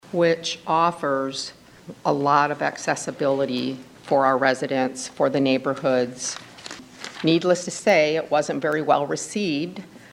COUNCIL MEMBER JULIE SCHOENHERR SUPPORTED THAT PLAN: